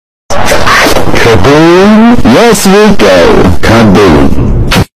KABOOM? YES RICO KABOOM [Meme BASSBOOSTED]
kaboom-yes-rico-kaboom-meme-bassboosted.mp3